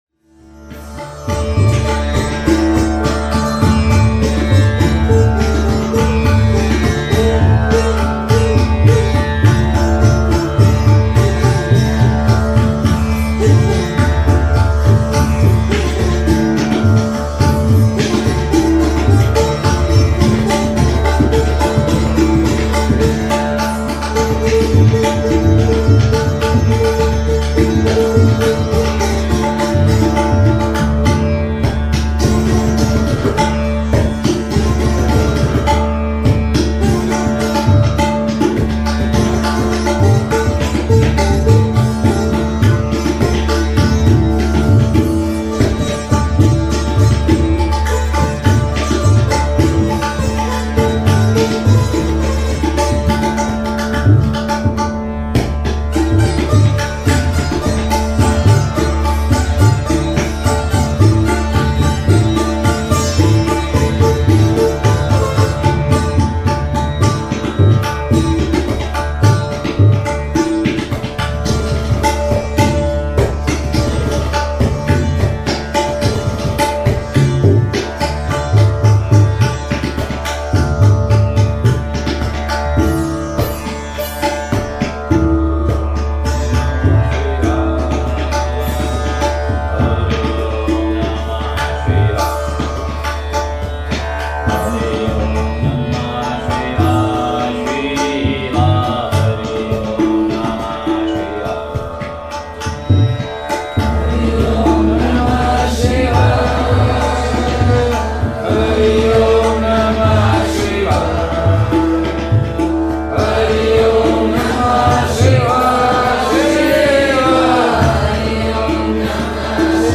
Kirtan, Prática e Sat Sanga
aqui excertos de um ensaio e de um concerto!